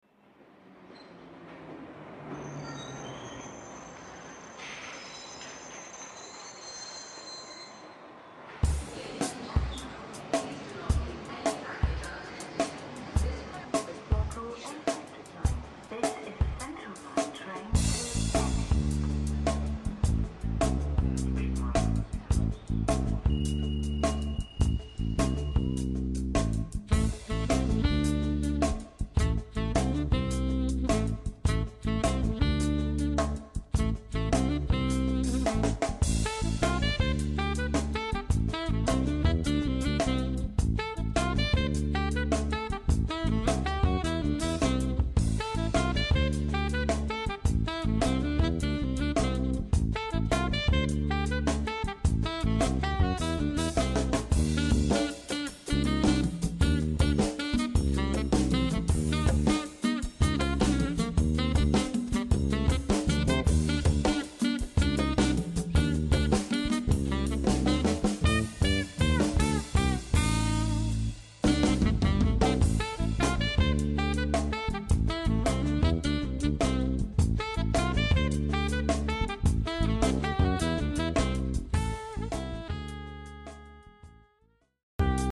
Alto & Soprano Sax
Electric Bass & Double Bass, Samples
Drums & Percussion